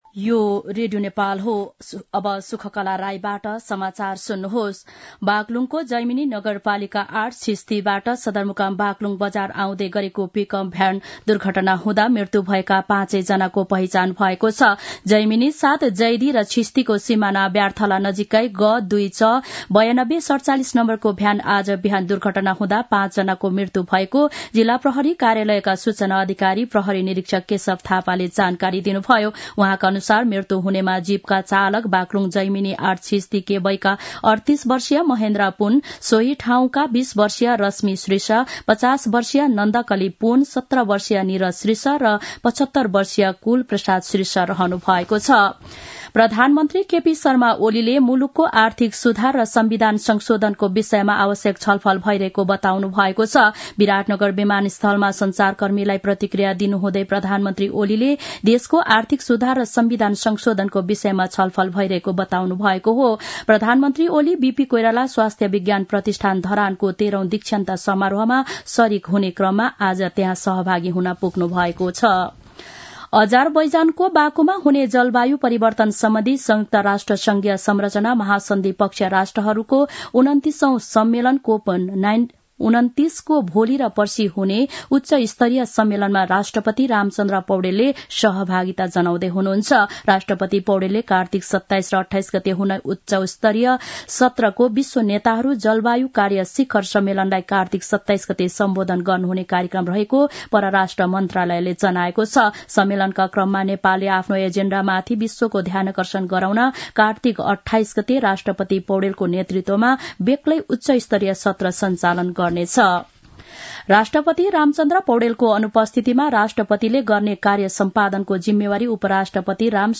दिउँसो १ बजेको नेपाली समाचार : २७ कार्तिक , २०८१